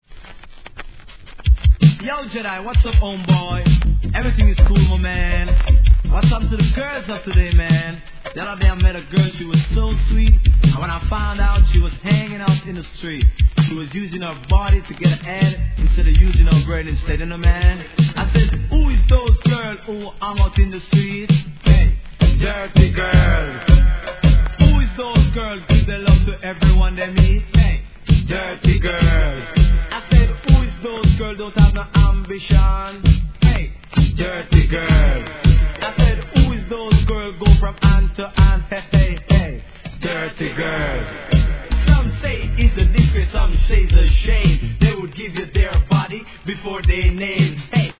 REGGAE
JAMAICAN RAP!